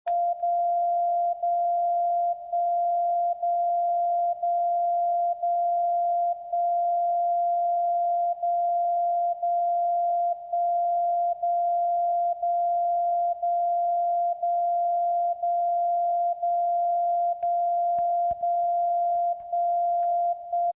• ▲ ▼ Ich bin ca. 160 km vom Sender entfernt. Das Signal im Anhang hab ich gerade aufgenommen.
DCF77.mp3